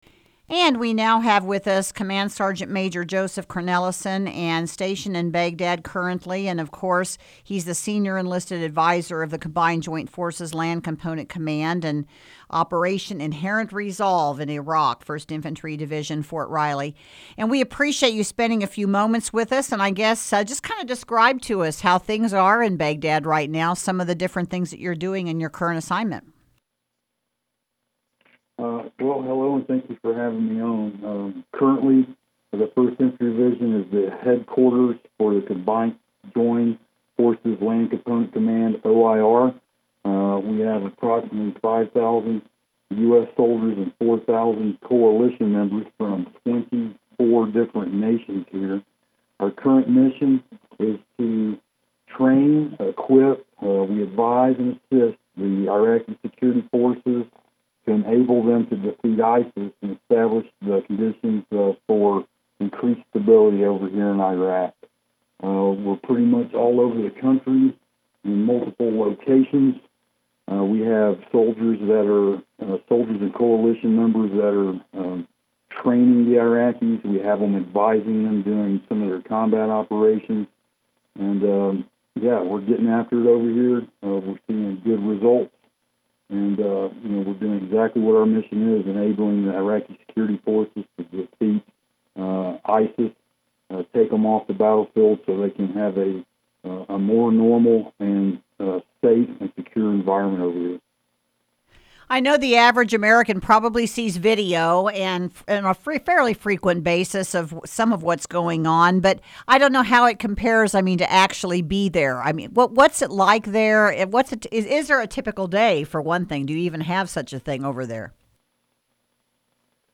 KMAN interviews soldier in Baghdad